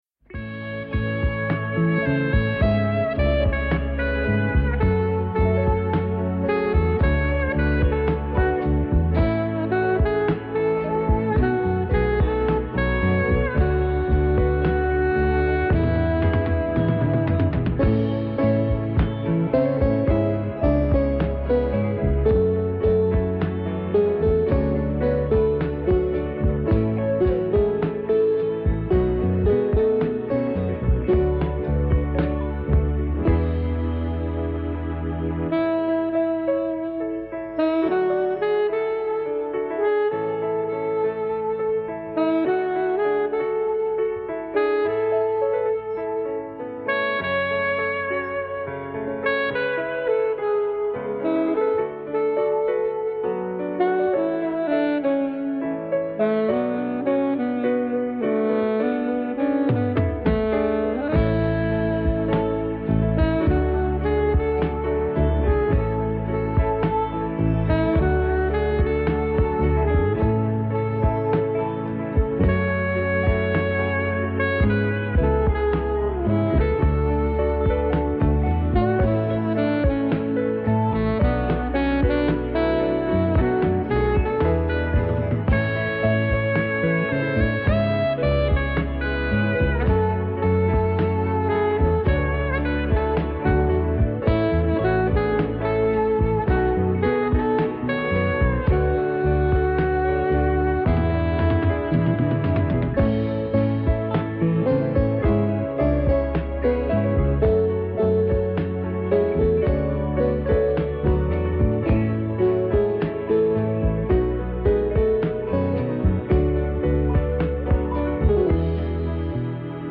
m�sica de los a�os 80